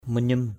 /mə-ɲum/